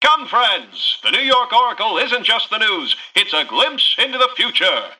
Newscaster_headline_01_alt_01.mp3